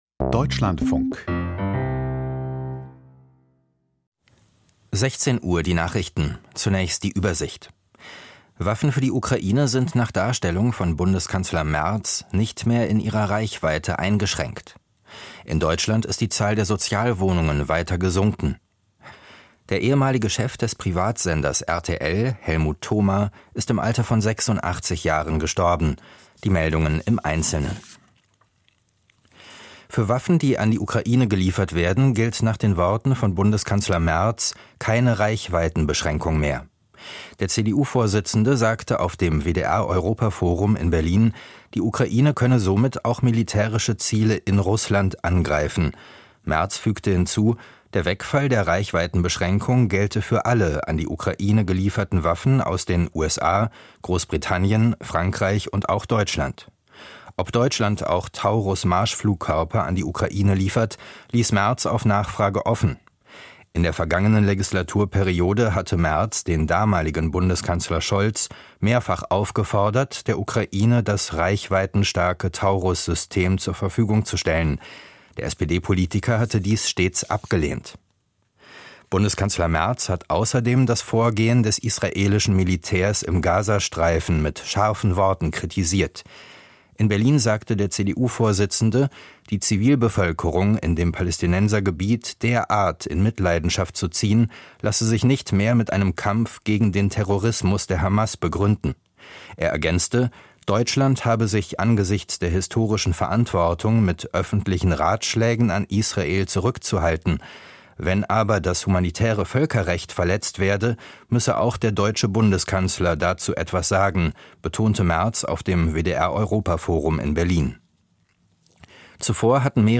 Büchermarkt 26.05.2025: Kritikergespräch zu Verena Stauffer + Sophia Klink - 26.05.2025